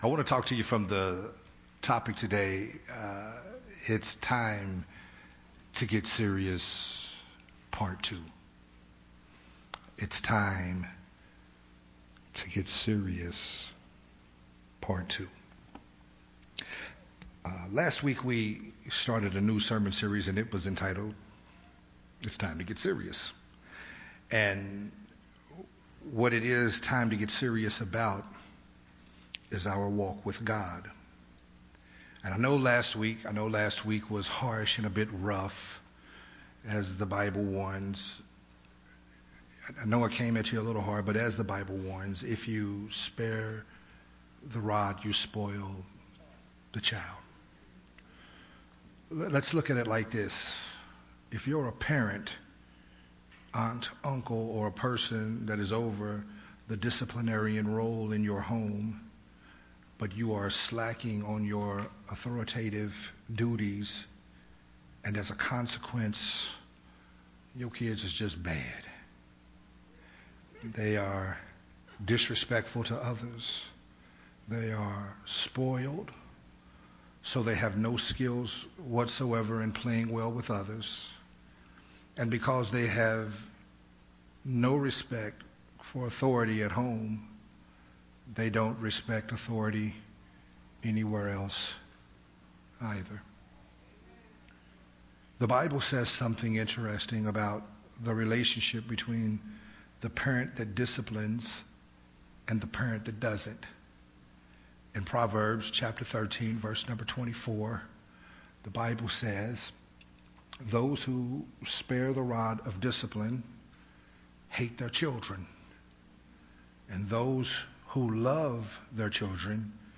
Part 2 of the sermon series
recorded at Unity Worship Center